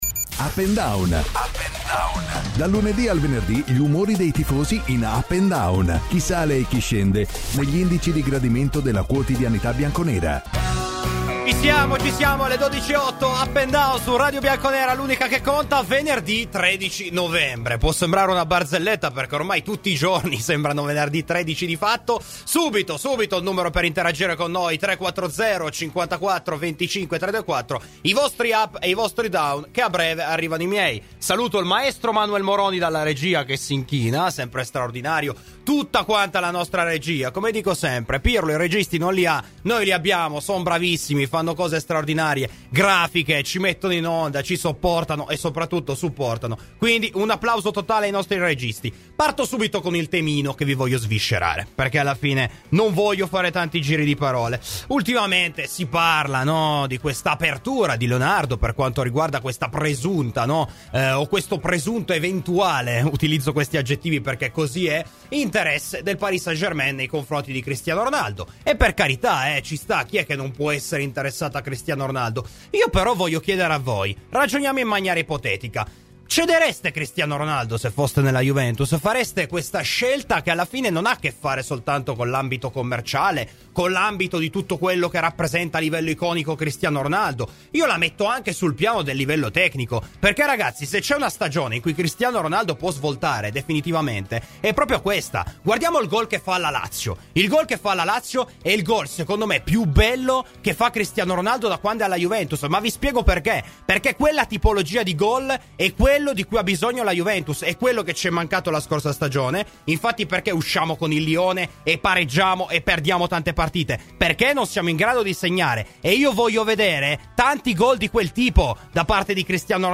Ai microfoni di Radio Bianconera, nel corso di ‘Up&Down’